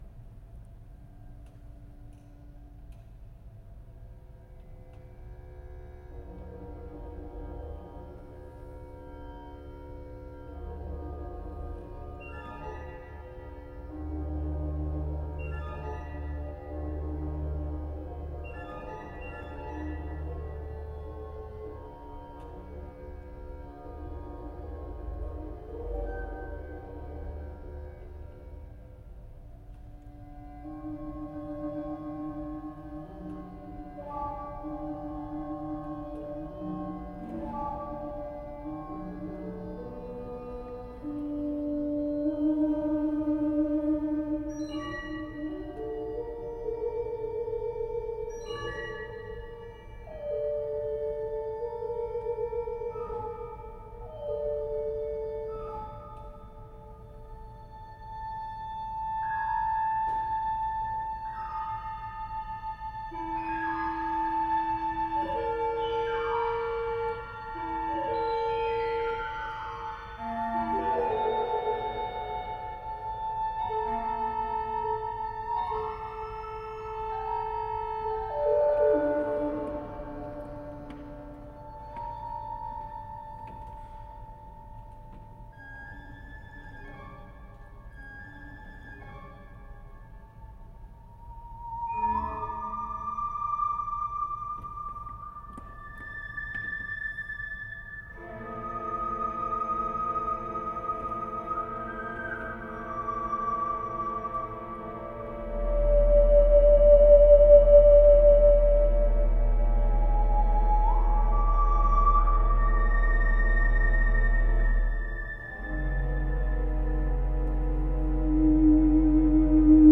Improvisation libre
ondiste
organiste